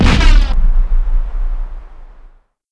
explo17a_shake.wav